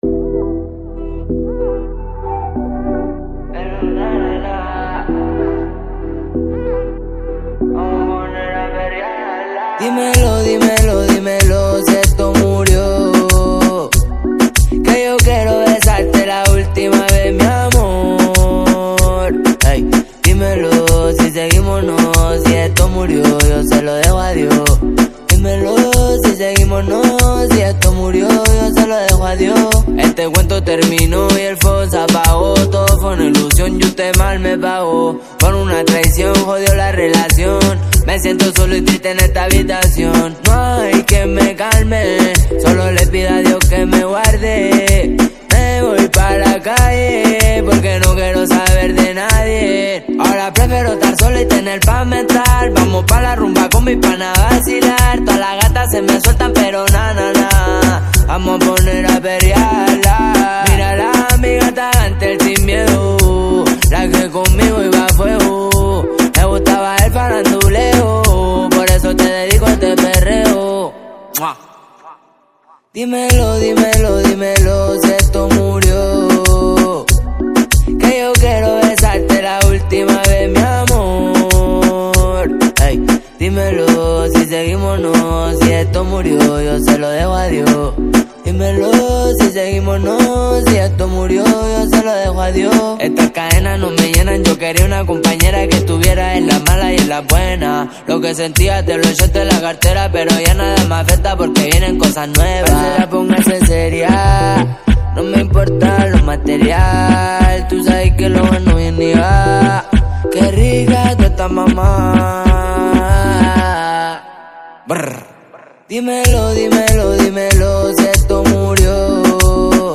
Carpeta: Reggaeton y + mp3